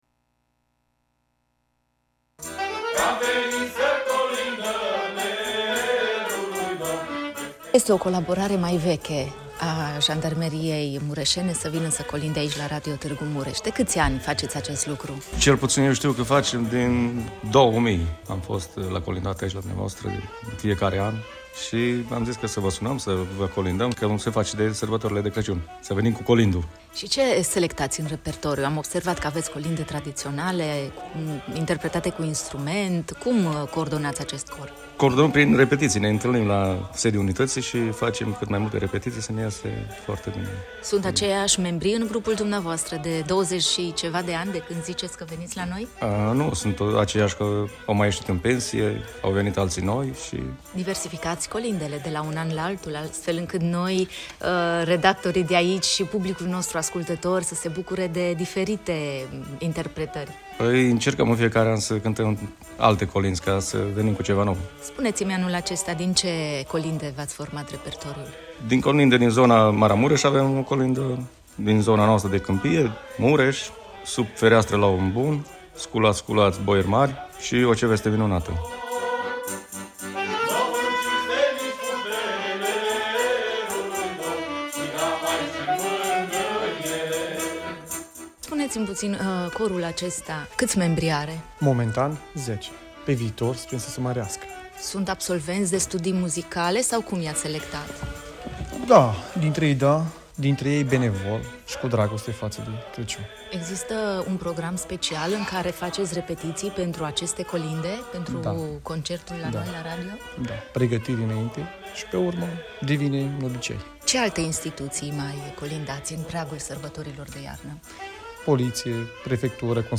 Corul Inspectoratului Județean de Jandarmi Mureș și Grupării de Jandarmi Mobile „Regele Ferdinand I” Târgu Mureș a colindat azi la Radio Târgu Mureș
Grupul, format din 10 agenți jandarmi, au interpretat câteva dintre colindele noastre tradiționale românești, atât vocal, cât și cu acompaniament instrumental.